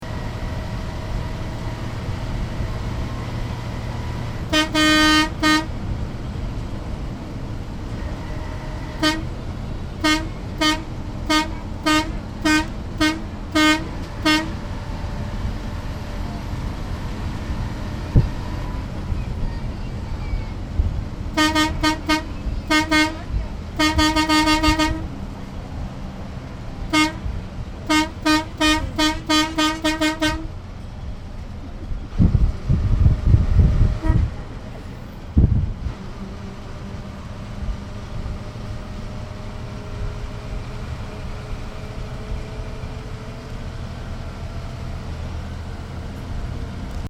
car.mp3